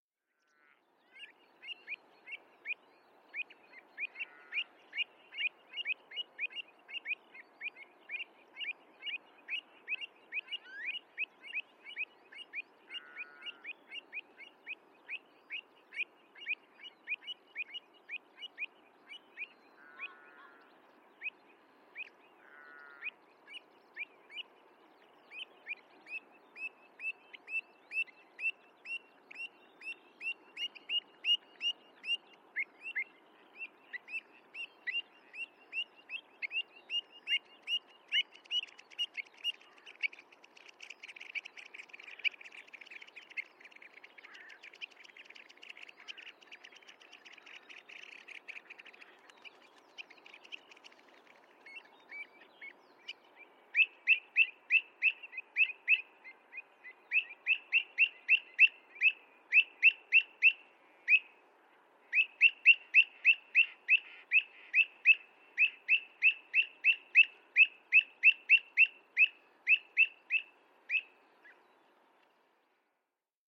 Avocets in Northumberland
Three pairs meeting up followed by alarm to Man with Dog. A difficult place to record, between the cars! Cresswell Northumberland 18.4.17. MKH8020/30/reflector/SD722 from the car.